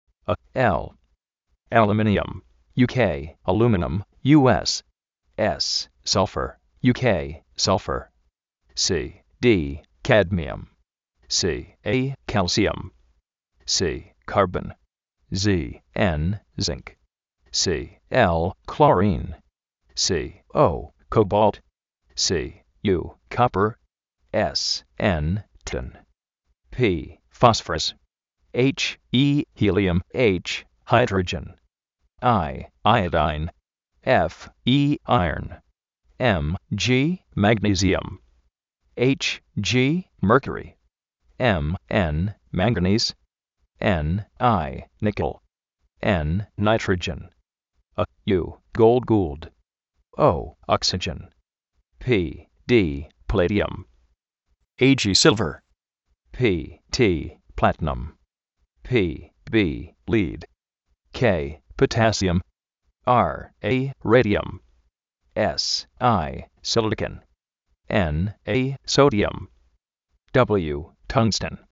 Aluminum (US)   alumínium (UK)
alúminum (US)
Sulfur (US)   sálfer (UK)
jáidroyen
áiodain